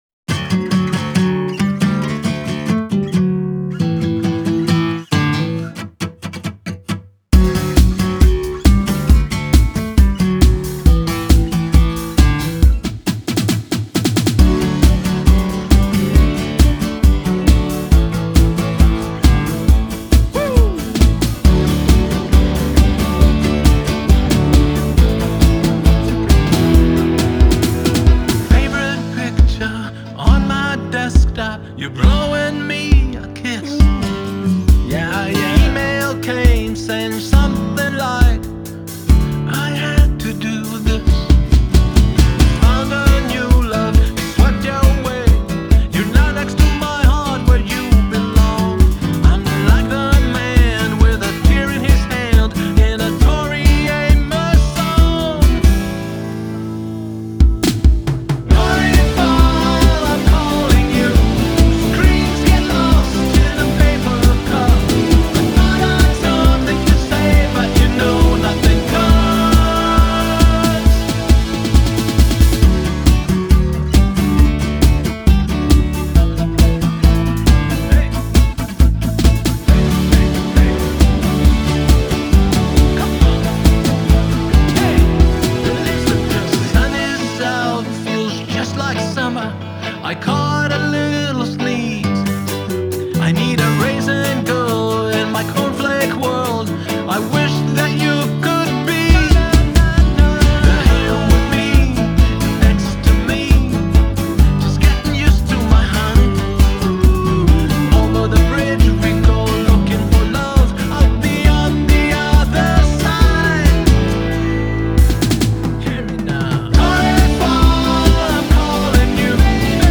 Genre: Pop Rock, Adult Alternative Pop/Rock